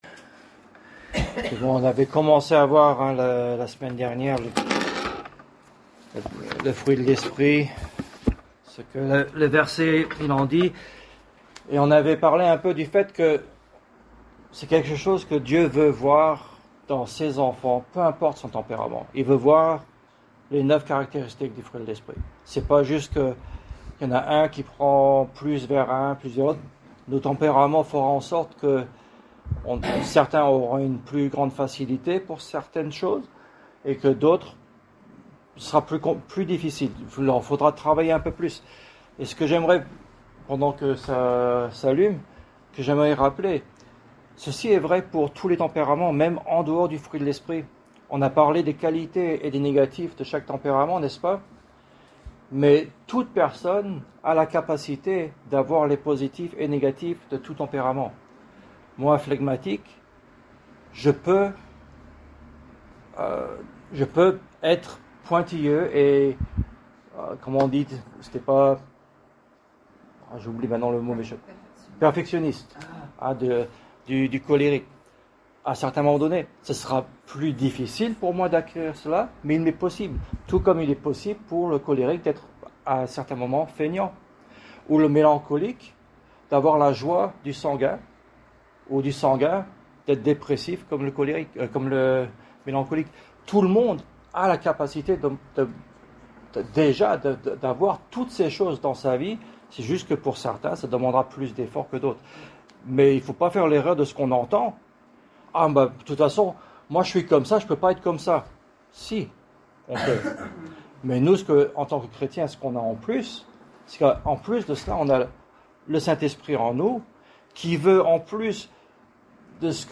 Genre: Etude Biblique